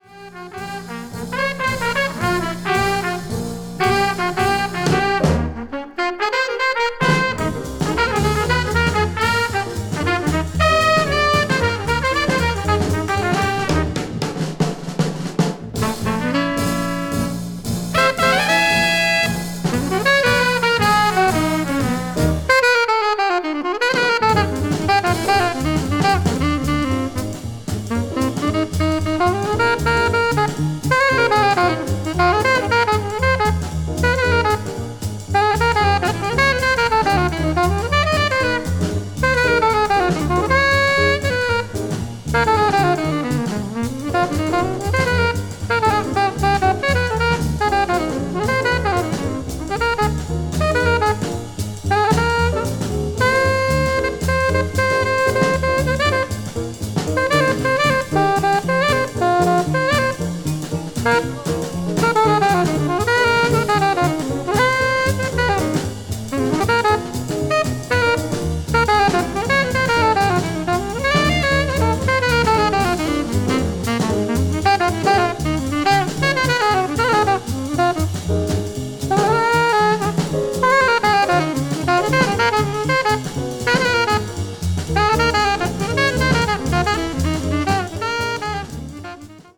blues jazz   hard bop   modern jazz